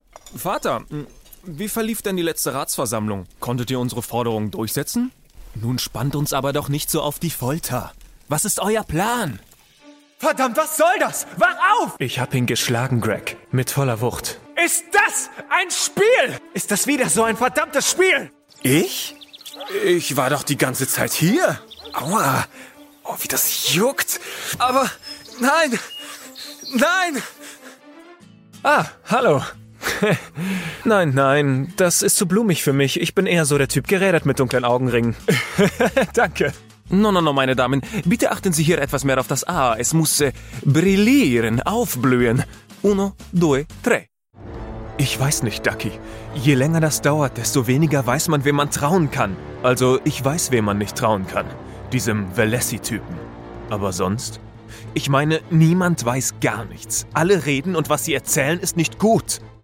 Voice Over Sprecher Off-Sprecher Deutsch Männlich Jung Frisch.
Sprechprobe: Sonstiges (Muttersprache):